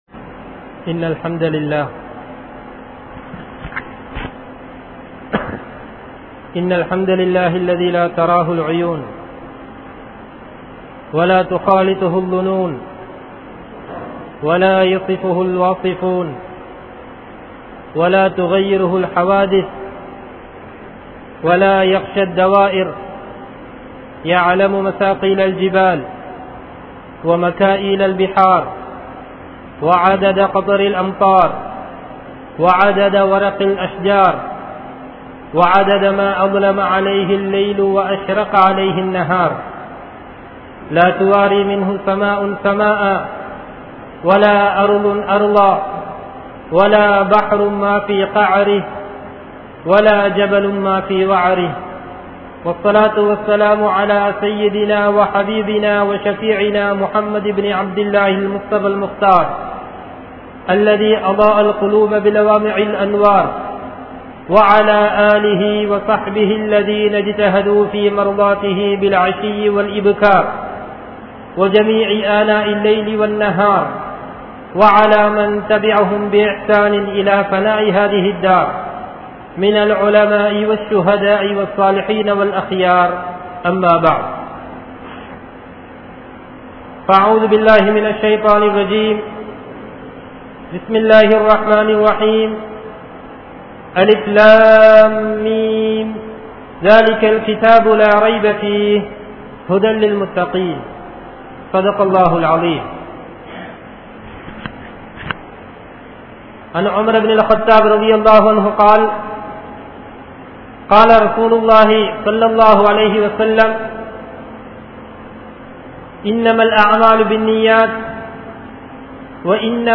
Islamiya Paarvaiel Kodukal Vaangal (இஸ்லாமிய பார்வையில் கொடுக்கல் வாங்கல்) | Audio Bayans | All Ceylon Muslim Youth Community | Addalaichenai